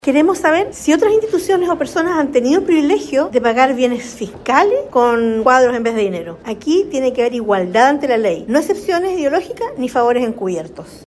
La diputada de RN, Marcia Raphael, aseguró que buscarán establecer si otras instituciones o personas también han pagado inmuebles fiscales con cuadros, como en este caso, llamando a respetar el principio de igualdad ante la ley.